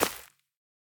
Minecraft Version Minecraft Version snapshot Latest Release | Latest Snapshot snapshot / assets / minecraft / sounds / block / azalea / break4.ogg Compare With Compare With Latest Release | Latest Snapshot